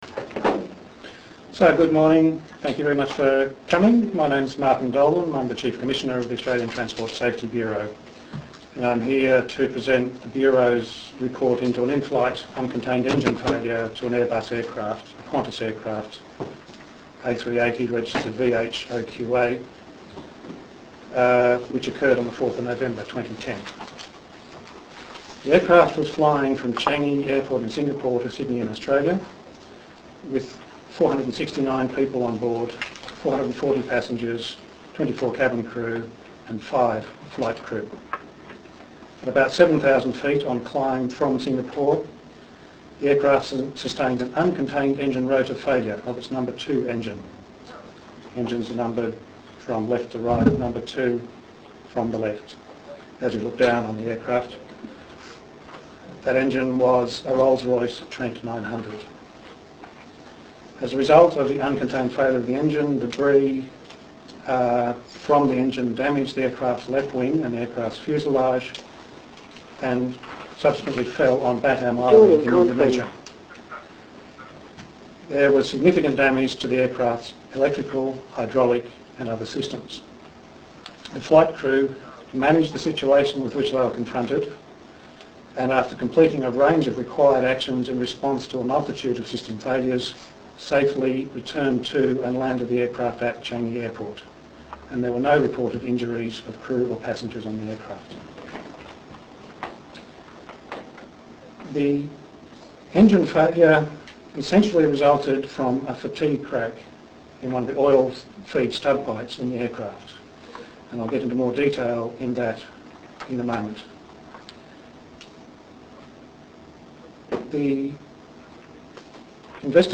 Audio of media conference 27 June2013